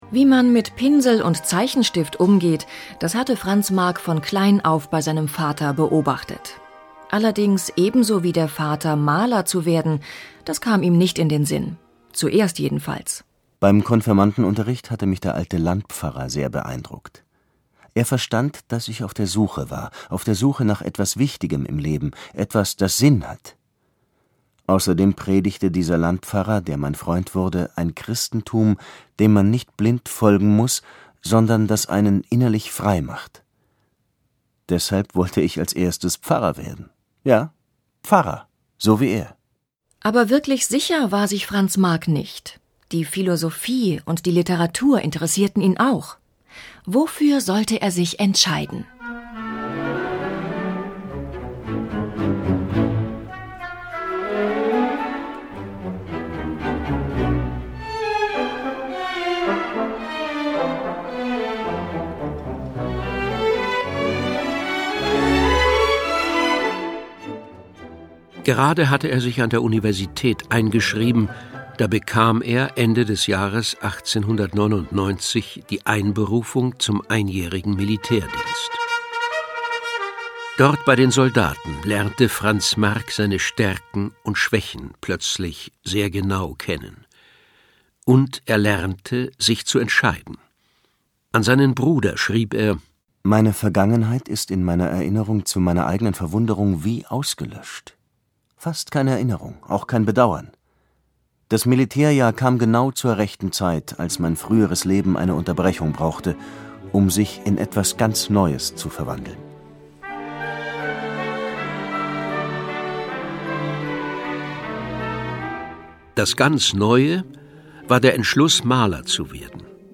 Hörbuch: Kunst-Stücke für Kinder.